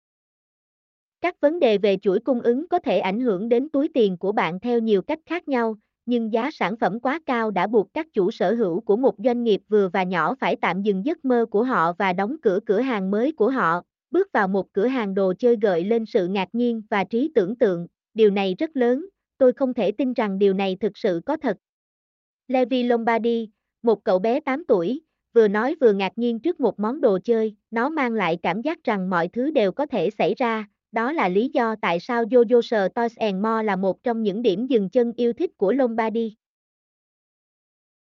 mp3-output-ttsfreedotcom-4.mp3